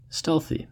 Ääntäminen
US : IPA : /ˈstɛlθi/